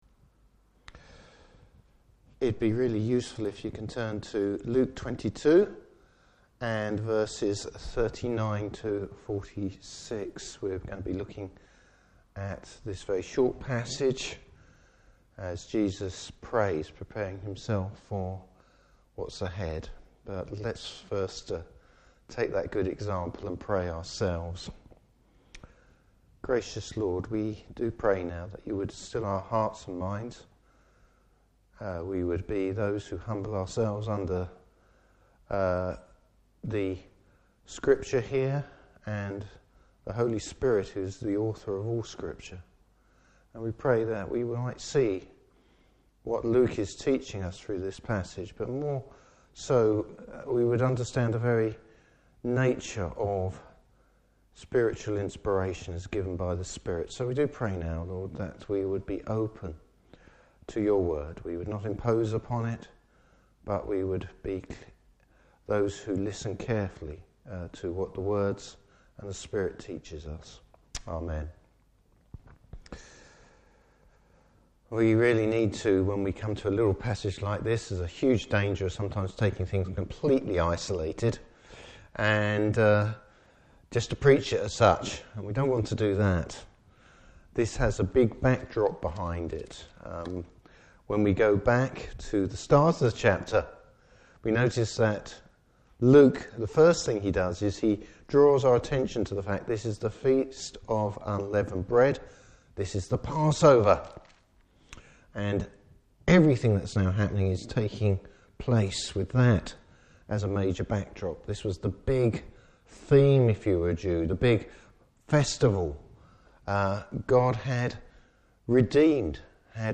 Service Type: Morning Service How Jesus prays a prayer of obedience.